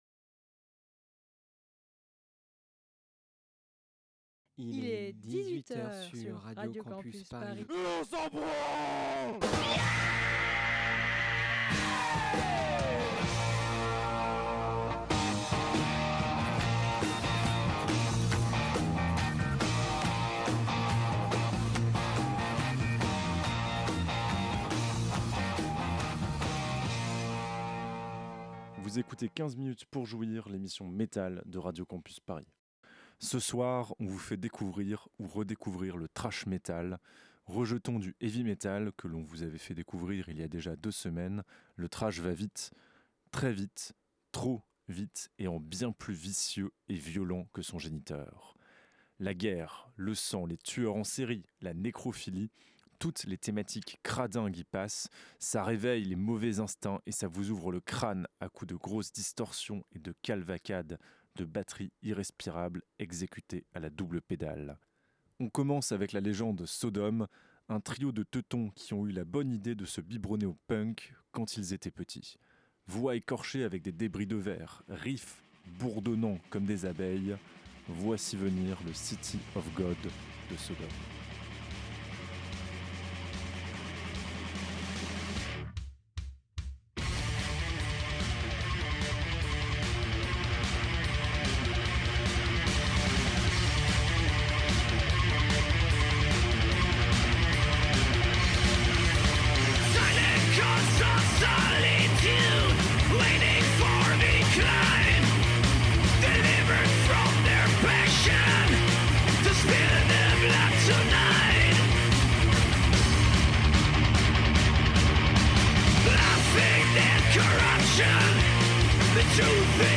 Pour ce sixième date avec toi, on va dans le sale, dans les ordures, le rugueux aux arrêtes acérées. Le Thrash metal, c'est le clochard hargneux du metal, le rebelle, l'énervé de la famille. Celui qui se fiche de faire dans le beau et le soyeux, et qui fait passer ses messages en force, sans mettre de vaseline.